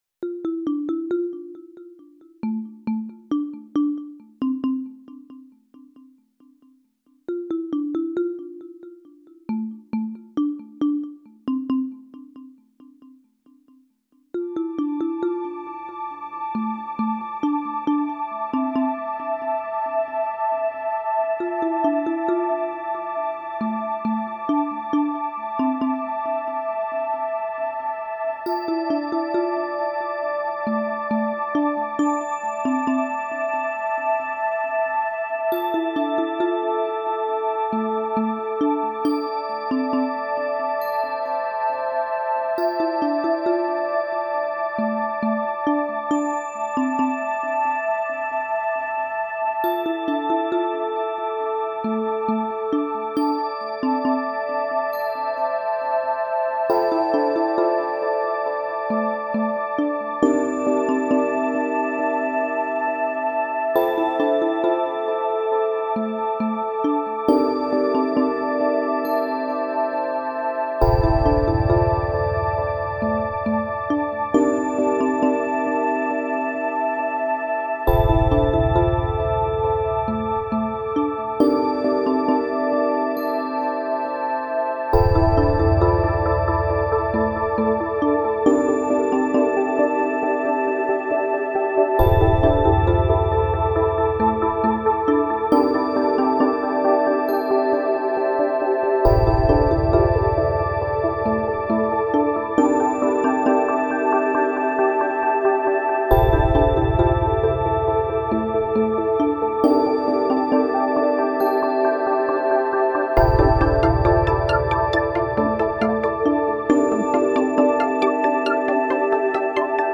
使用している機材はＰＣと入力用のＵＳＢ－ＭＩＤＩキーボード、それにオーディオインターフェイスだけです。
曲調はアンビエントです。